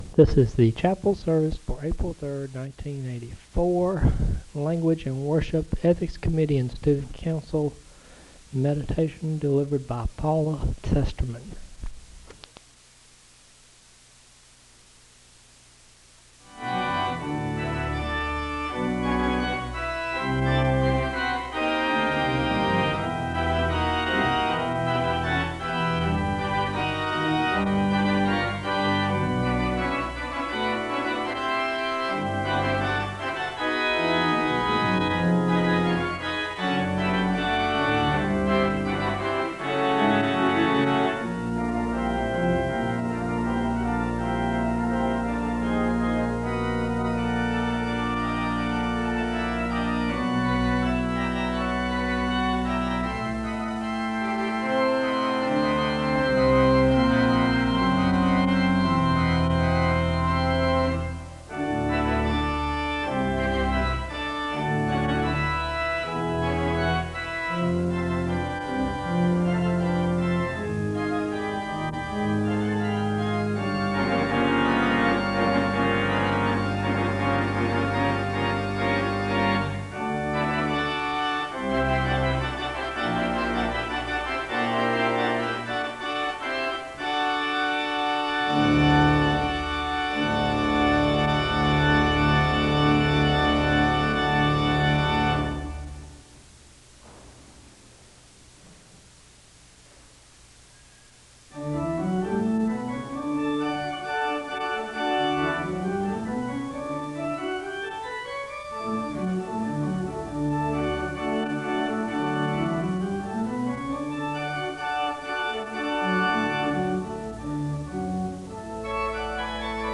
The service begins with organ music (00:00-06:45).
A soloist sings a song of worship (21:10-24:27). Two committee members recite a prayer (24:28-25:47). The committee recite three liturgies that celebrate diversity and social equality (25:48-30:54).
Location Wake Forest (N.C.)